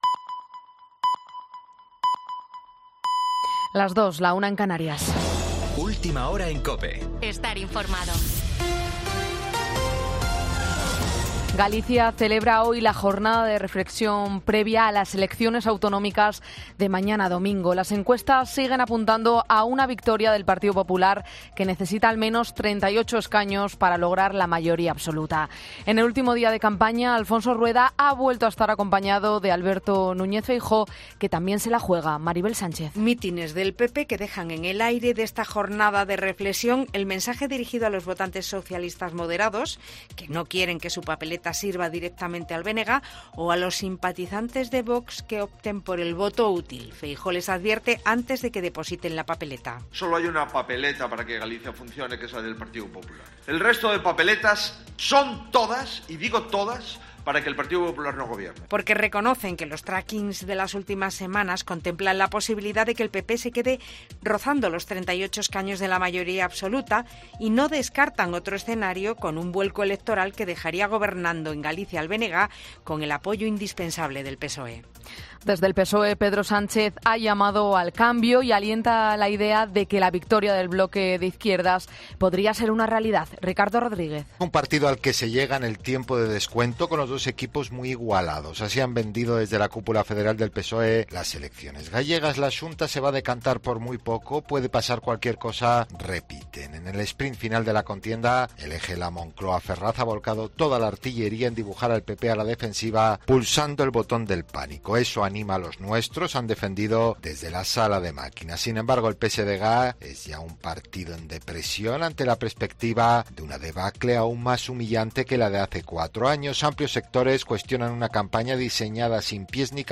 AUDIO: Boletín 02.00 horas del 17 de febrero de 2024